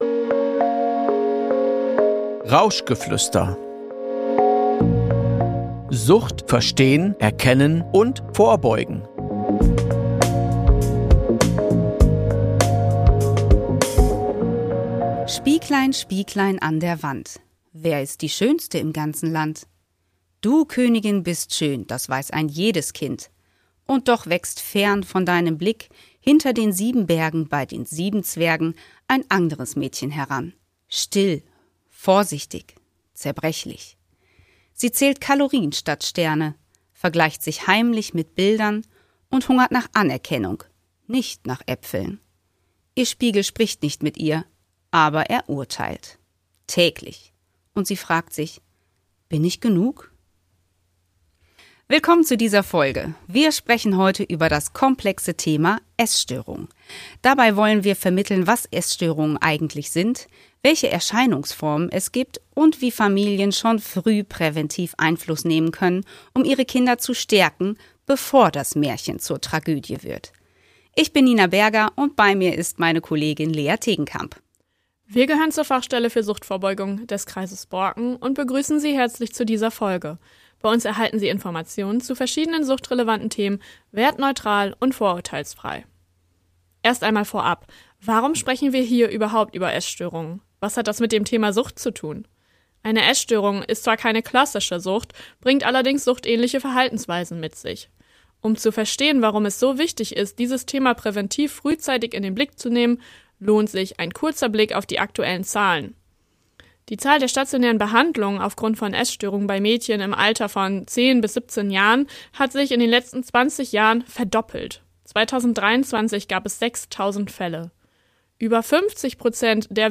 Ein Gespräch, das informiert, einordnet und stärkt – für alle, die mit jungen Menschen im Austausch bleiben wollen.